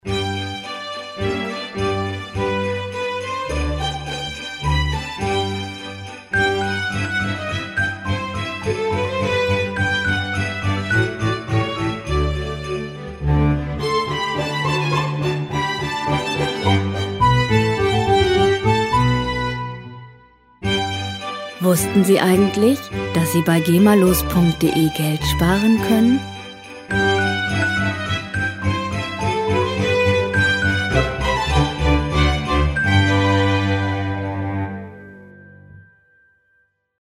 Musikstil: Klassik
Tempo: 105 bpm
Tonart: G-Dur
Charakter: sanft, melodisch
Instrumentierung: Streichquartett